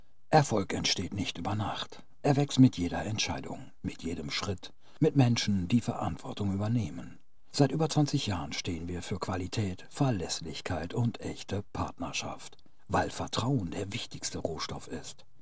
Imagefilm
Studio-quality recordings.